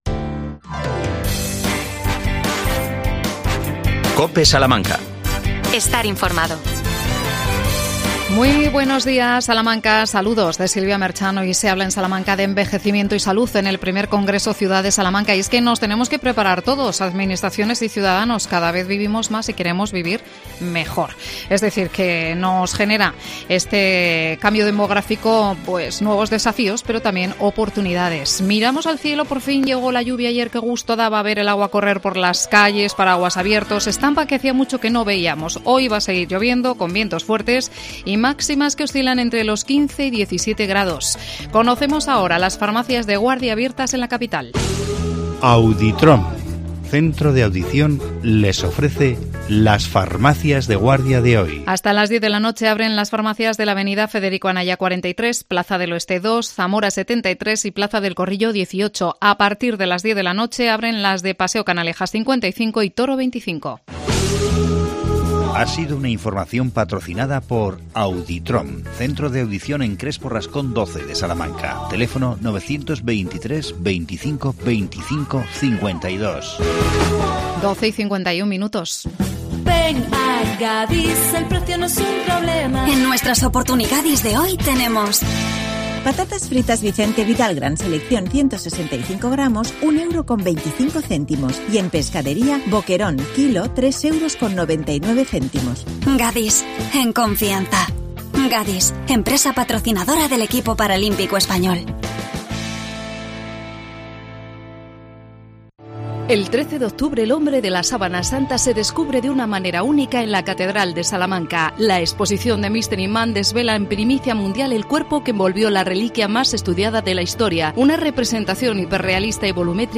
AUDIO: Entrevistamos